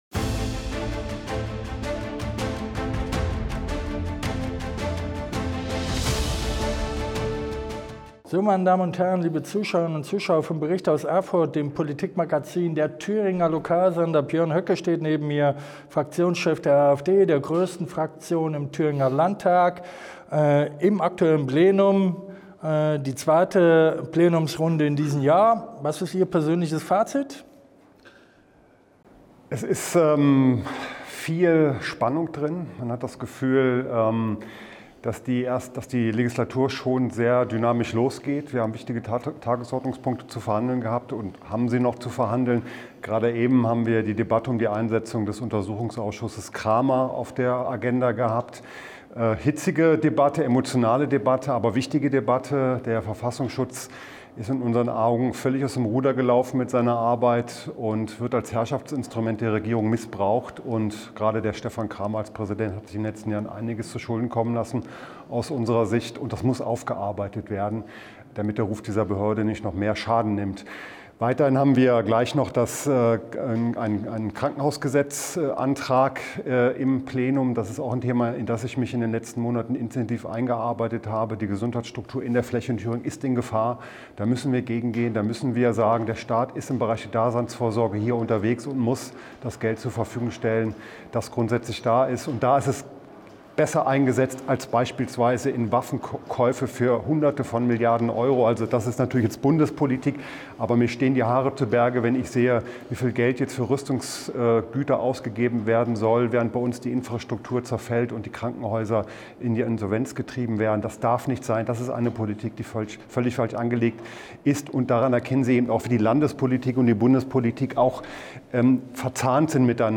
An dieser Stelle dokumentieren wir die Rede des Th�ringer Ministerpr�sidenten Mario Voigt (CDU) zum AfD-Antrag.